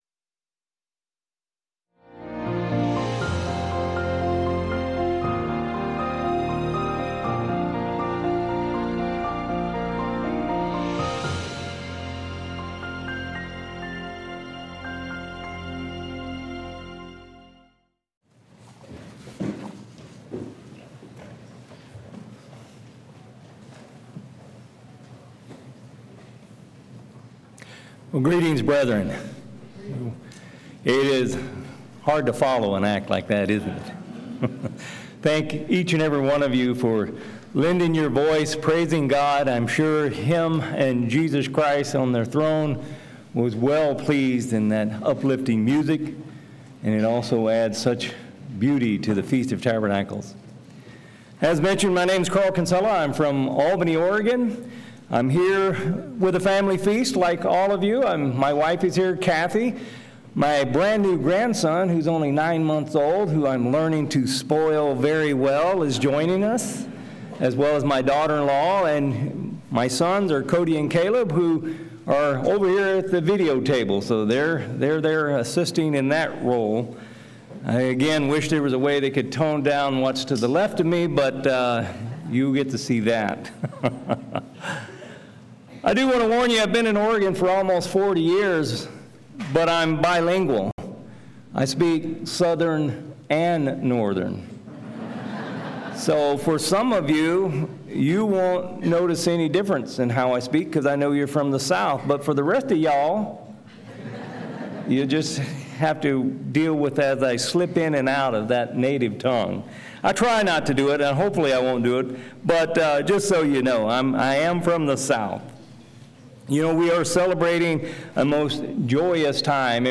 This sermon was given at the Spokane Valley, Washington 2023 Feast site.